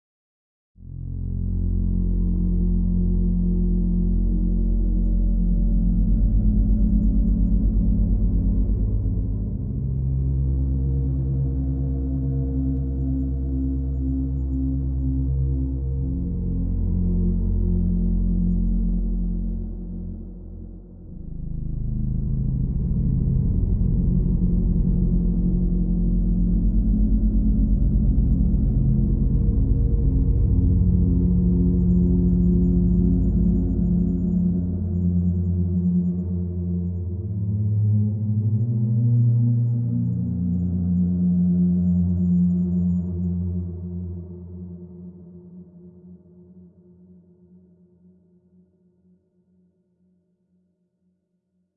描述：录音："一个简短的管弦乐声音重复了3次深沉而有力的低音。
标签： 电影 低音 底色 黑色 音乐 乐团 加工 氛围 弦乐 大气
声道立体声